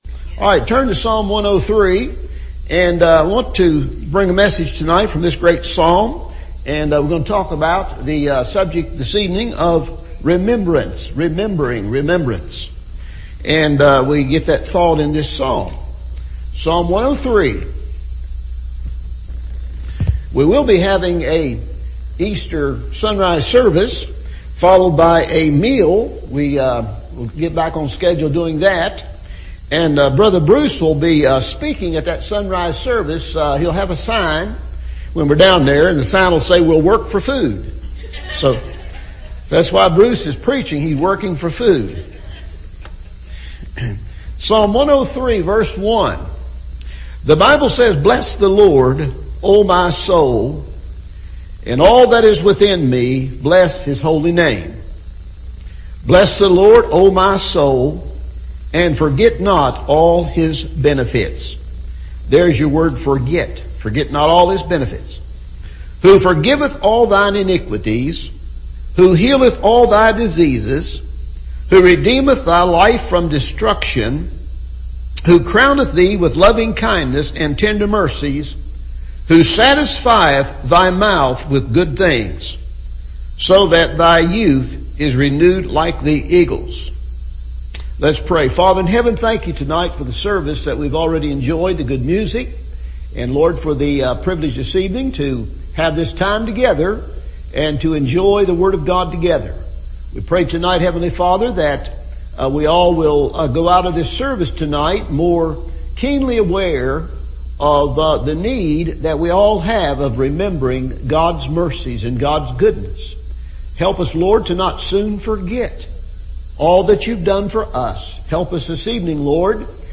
Remember – Evening Service